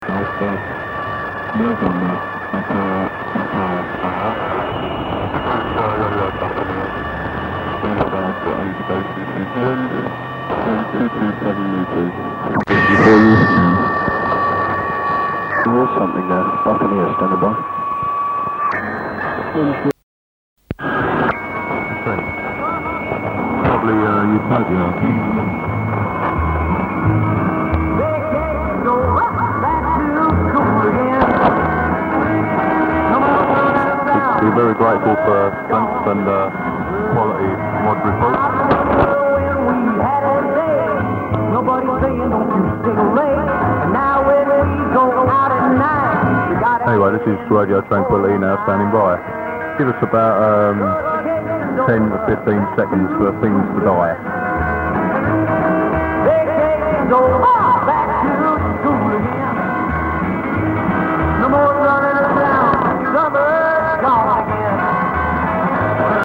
RADIO TRANQUILITY HEARD IN SCOTLAND:
I knew it was a London pirate by the accent, although quite why I never pursued the log at the time is anyone's guess.
Radio Tranquility. They were in QSO with Radio Buccaneer, which was heard a few times north of the border.